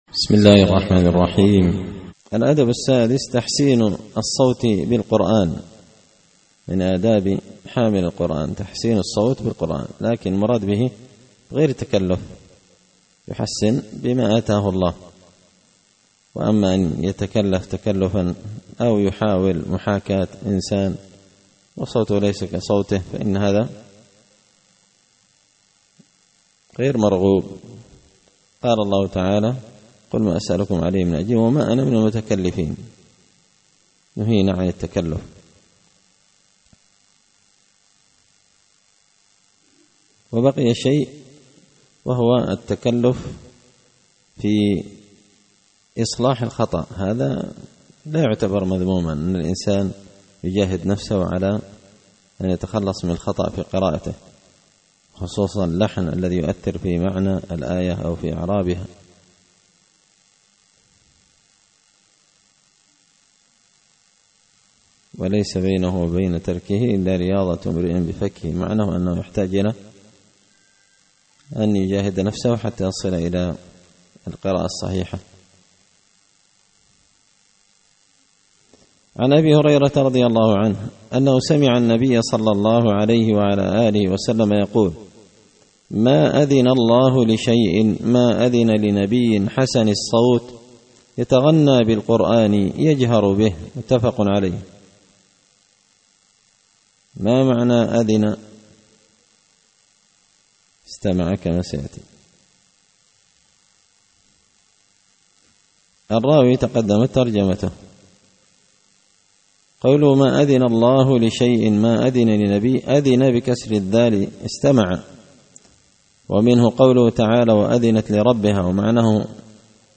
الدرس الثالث السبعون
دار الحديث بمسجد الفرقان ـ قشن ـ المهرة ـ اليمن